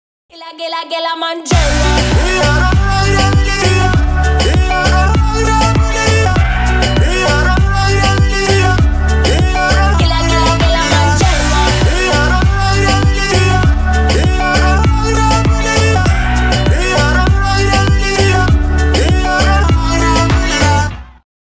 Стиль: Pop, R'n'B Ура!